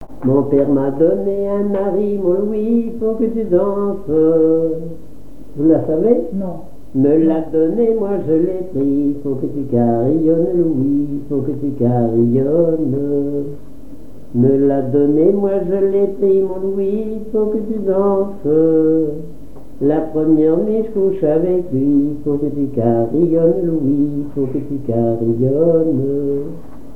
danse : ronde à la mode de l'Epine
Conversation autour des chansons et interprétation
Pièce musicale inédite